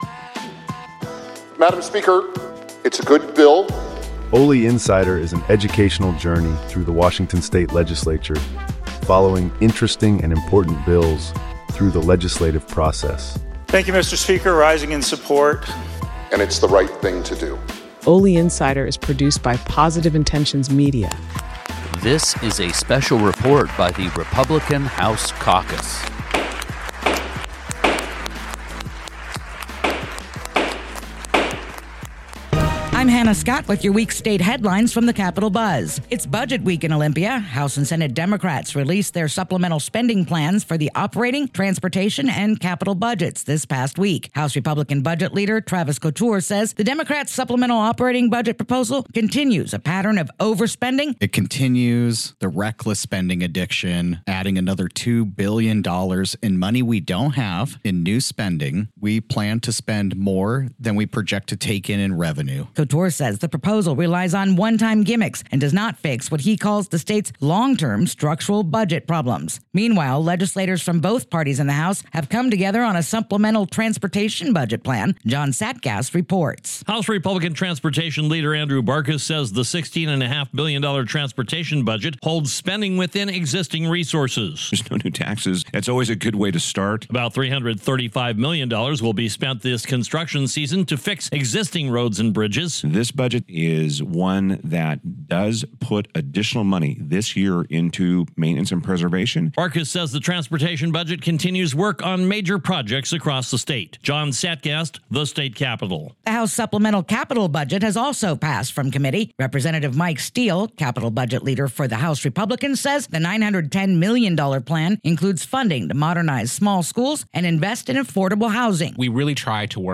This is a rebroadcast of the Capitol Buzz weekly report.